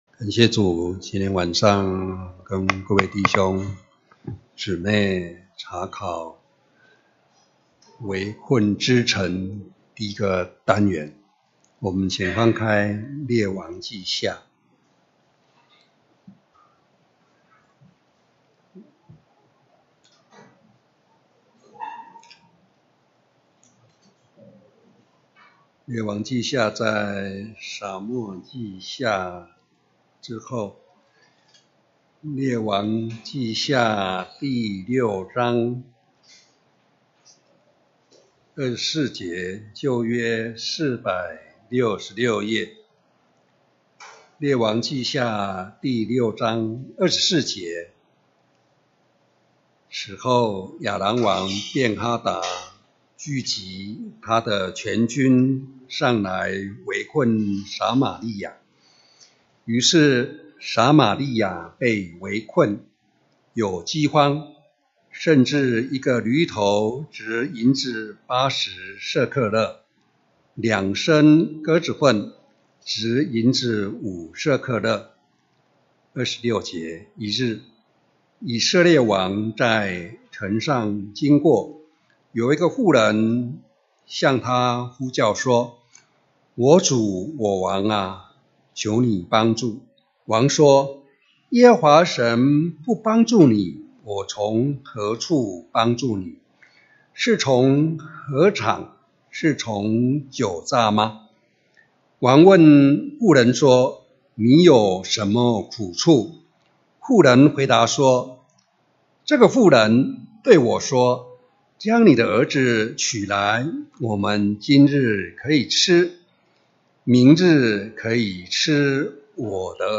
2018年2月份講道錄音已全部上線